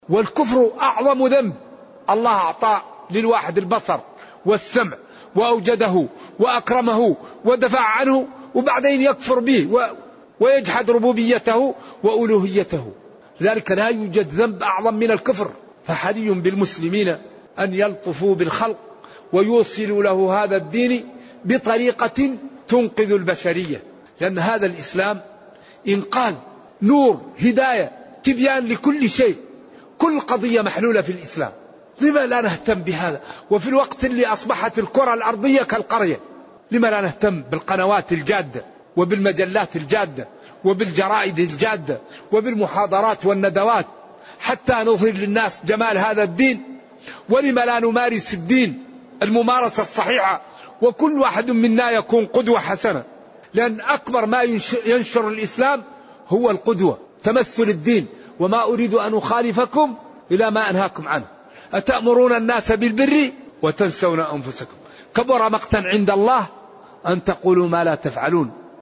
قطعة من المجلس الأول من تفسير سورة التوبة. التصنيف: التفسير